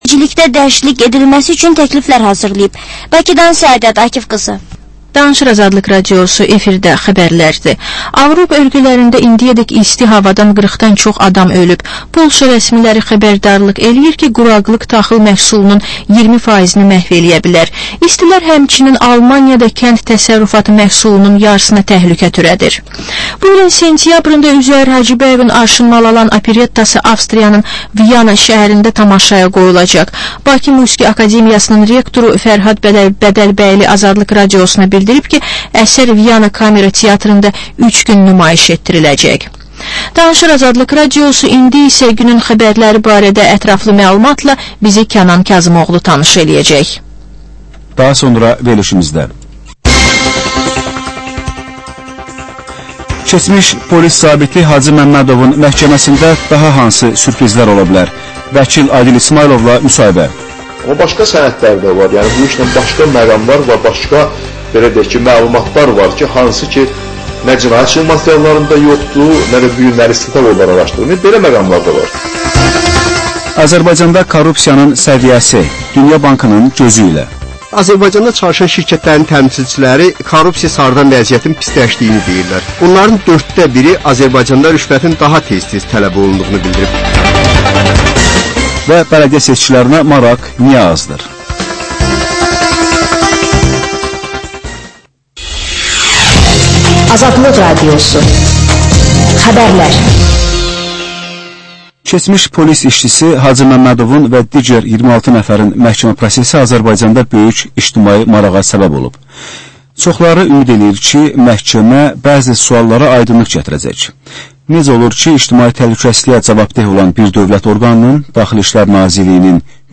Reportaj, təhlil, müsahibə